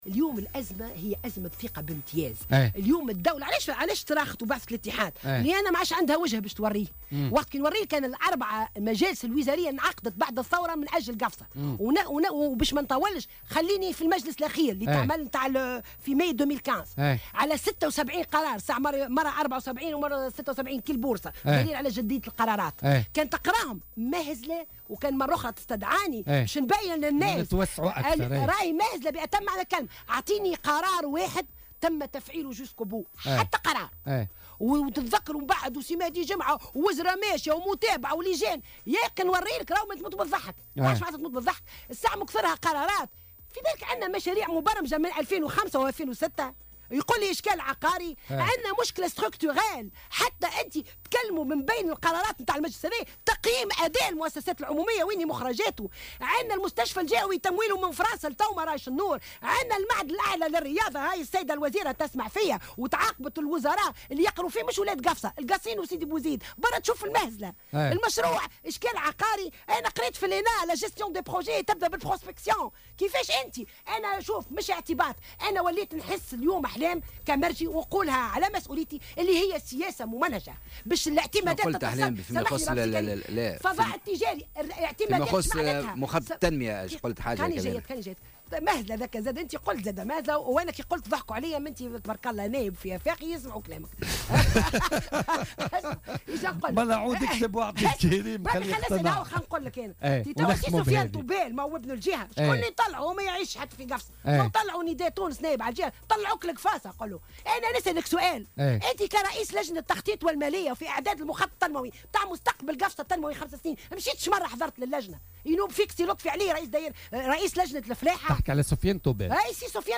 وأضافت خلال استضافتها اليوم في برنامج "بوليتيكا"، ان 4 مجالس وزارية انعقدت بعد الثورة حول الوضع في قفصة، و"تم إثرها إعلان قرارات مهزلة بأتم معنى الكلمة". وقالت إن البرامج موجودة والاعتمادات موجودة كذلك، إلا أنها لن ترى النور أبدا، وكأنها سياسة ممنهجة، على حد تعبيرها.